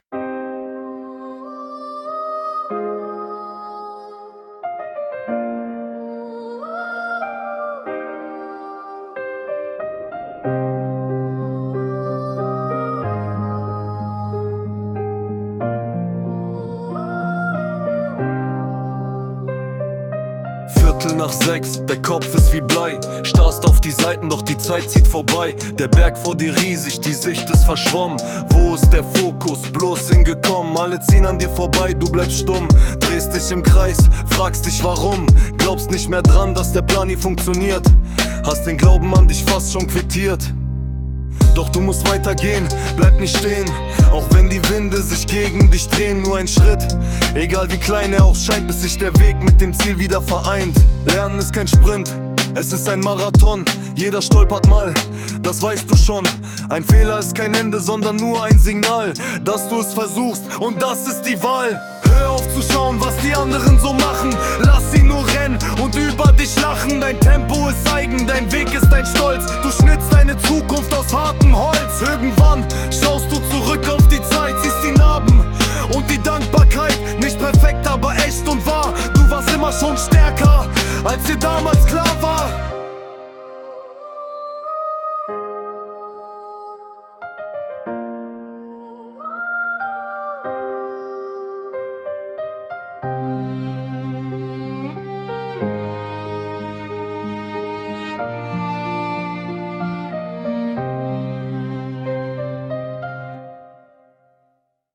Die musikalische Realisation erfolgt mithilfe von künstlicher Intelligenz, wobei die genauen Quellen den jeweiligen Songseiten hinzugefügt werden.
Motivationsrap.mp3